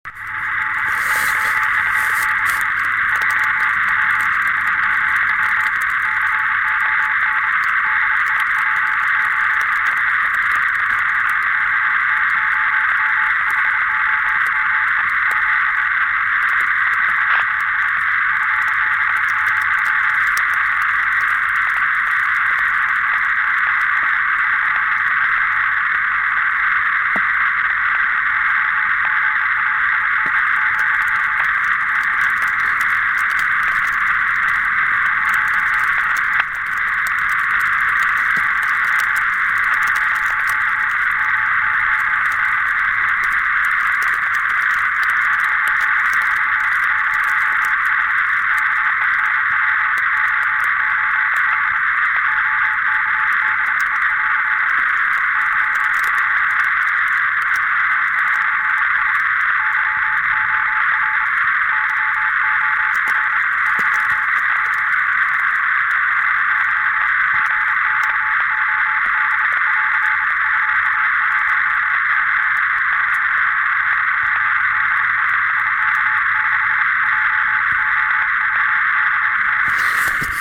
Выходил сегодня в обед поработать в лесах- полях QRP/p.